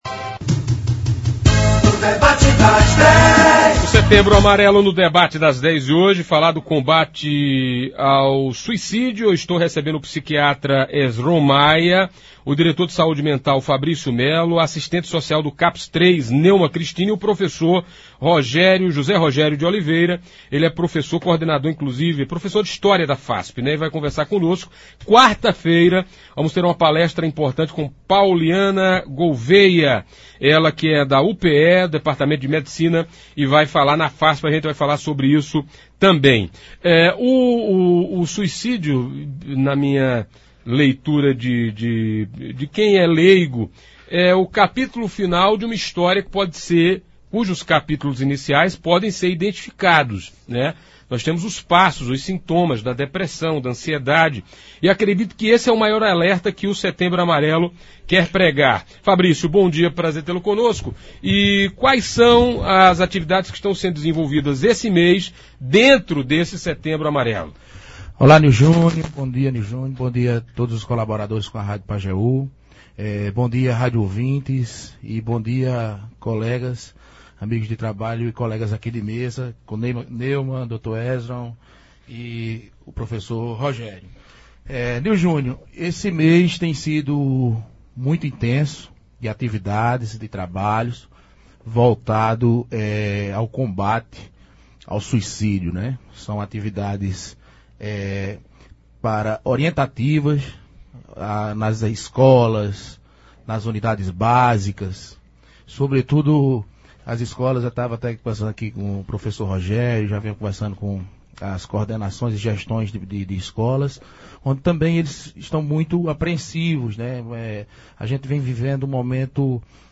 Ouça abaixo a íntegra do debate de hoje: